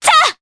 Artemia-Vox_Jump_jp.wav